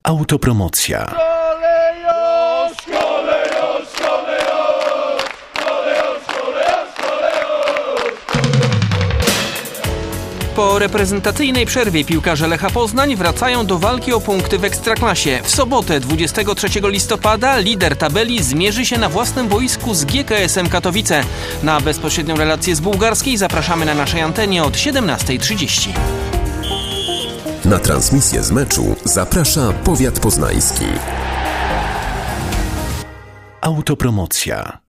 Radiowe zapowiedzi meczów Lecha w sezonie 2023/2024